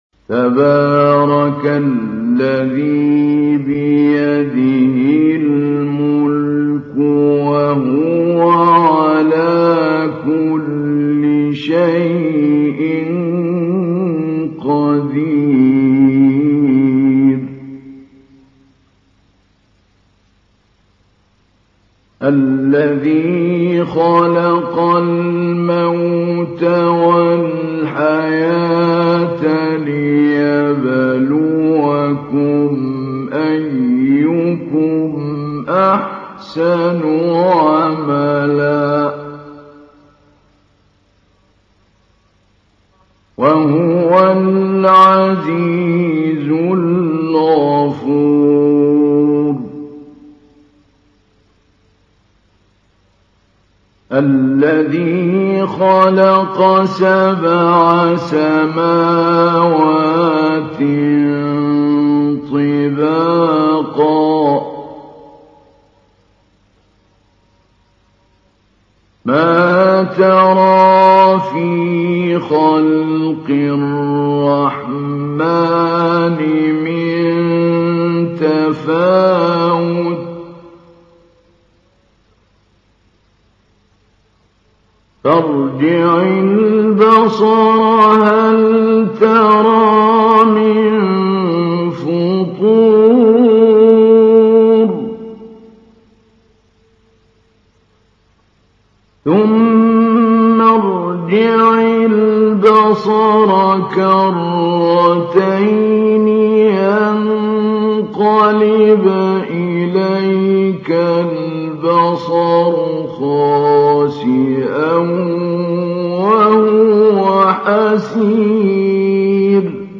تحميل : 67. سورة الملك / القارئ محمود علي البنا / القرآن الكريم / موقع يا حسين